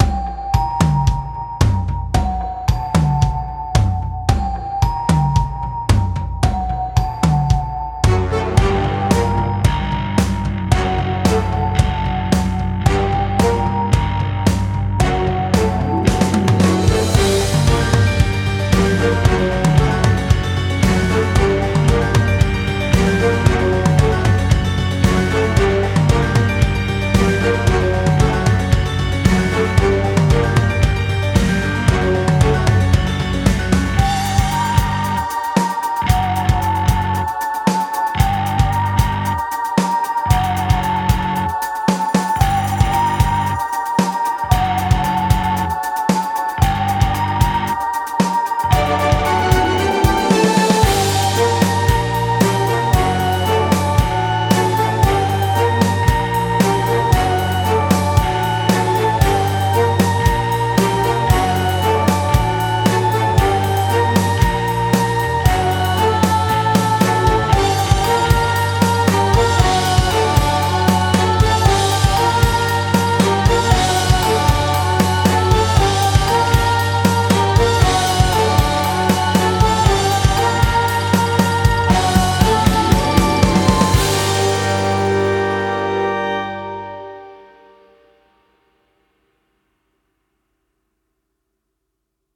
Genre: classicrock, classical.